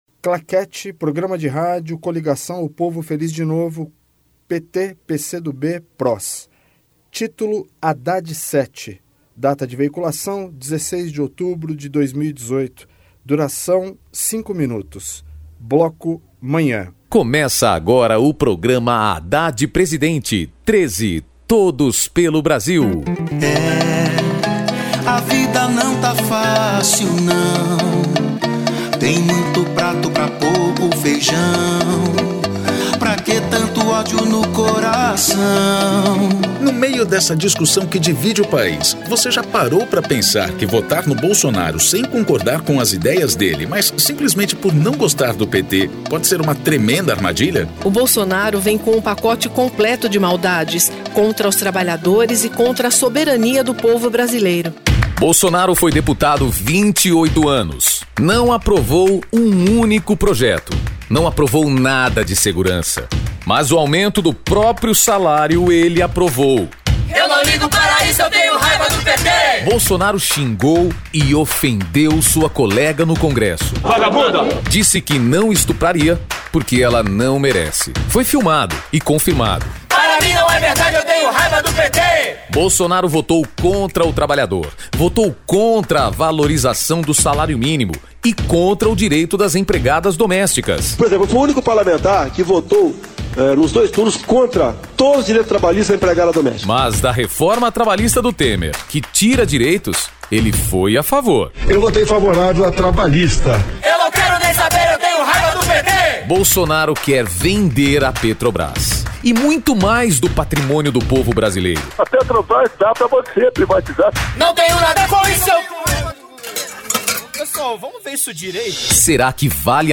Gênero documentaldocumento sonoro
Descrição Programa de rádio da campanha de 2018 (edição 37), 2º Turno, 16/10/2018, bloco 7hrs